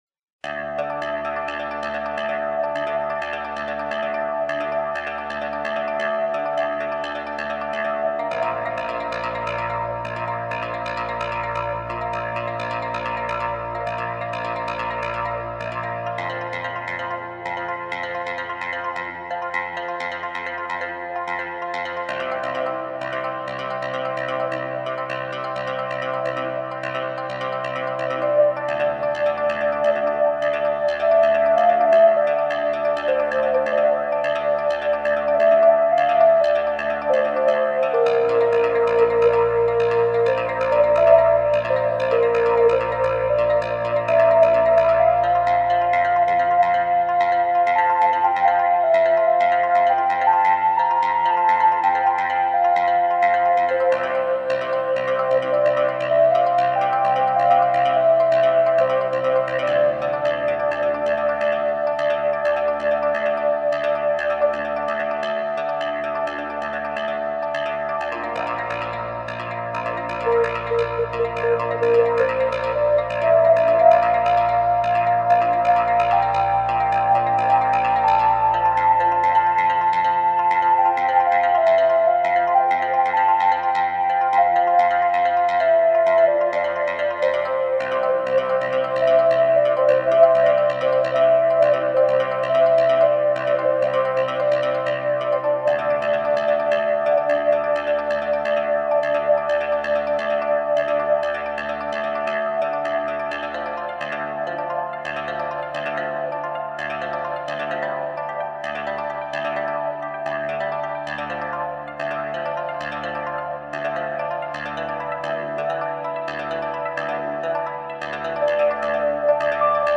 Восточный гипноз.
Медитативная музыка Медитация Музыка медитации